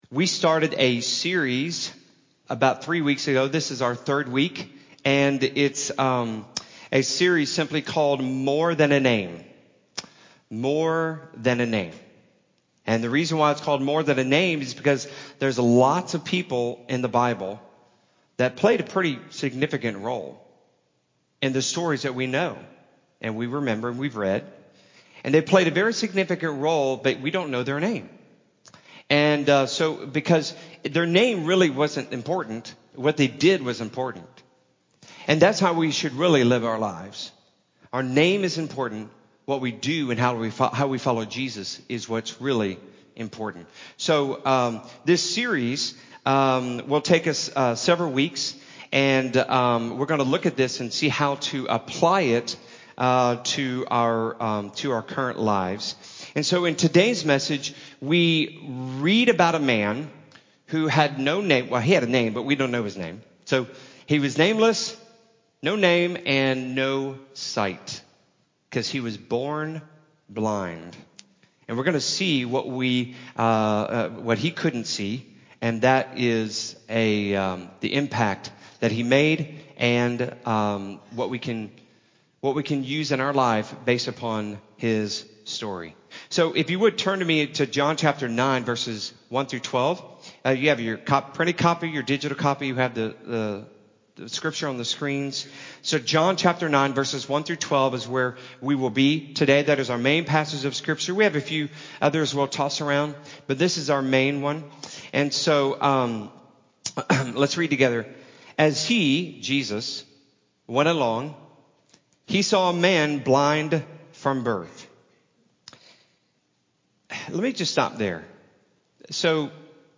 No-Name-and-No-Sight-Sermon-CD.mp3